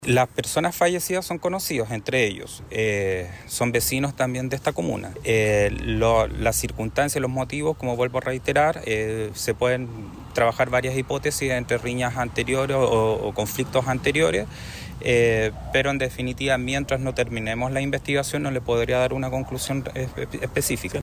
El Fiscal del Equipo Contra el Crimen Organizado y Homicidios (ECOH), Leonardo Tapia, indicó que no se descarta que el ataque haya sido motivado por rencillas anteriores.